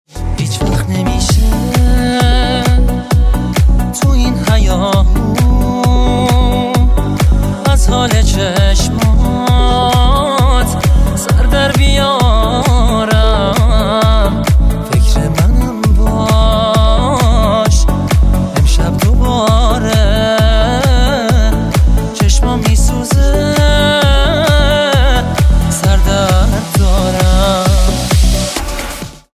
رینگتون پرانرژی و احساسی باکلام